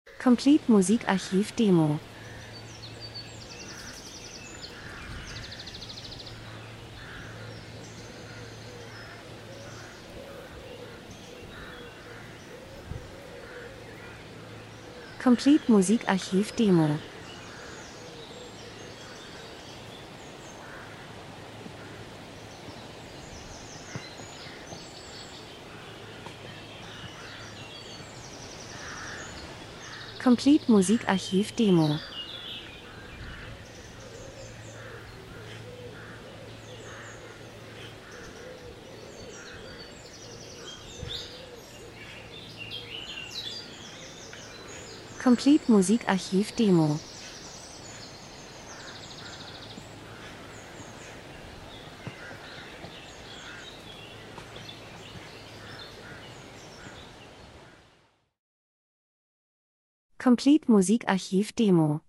Herbst -Geräusche Soundeffekt Athmos, Vögel, Wiese 01:00